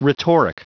Prononciation du mot rhetoric en anglais (fichier audio)
Prononciation du mot : rhetoric